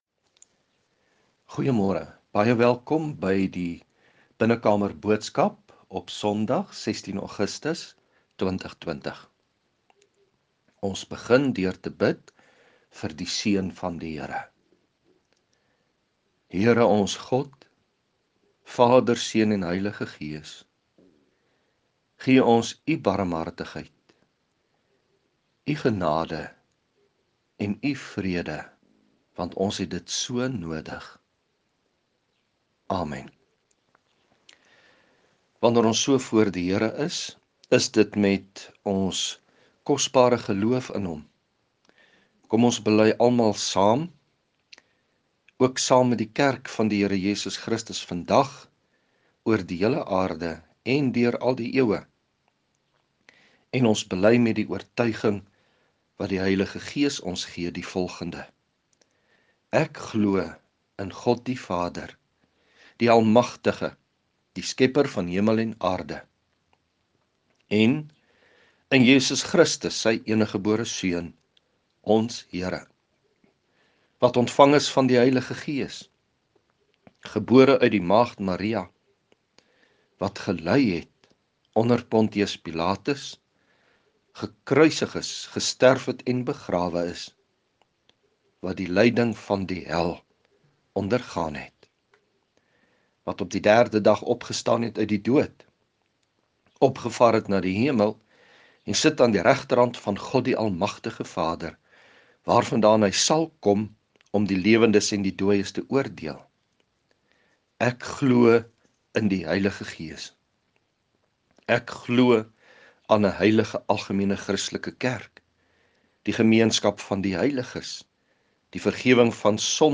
Vanoggend het ons ‘n erediens in ‘n grendeltyd.